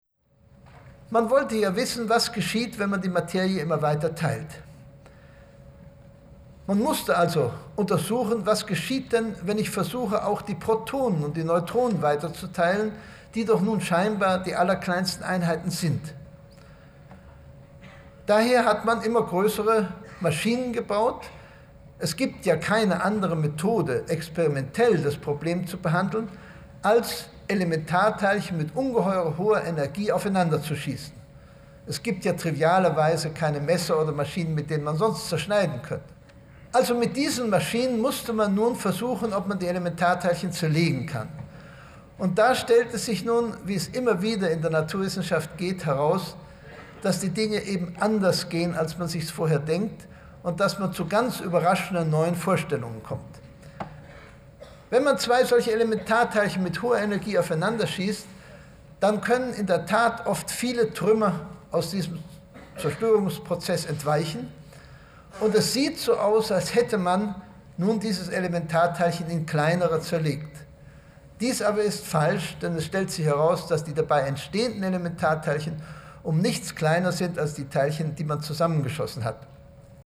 Originaltonaufnahmen 1951–1967
"Mit hoher Stimme und in großer Klarheit spricht Heisenberg darüber, wieviel schwerer es ist, alte Begriffe aufzugeben als neue zu finden." (Berliner Zeitung)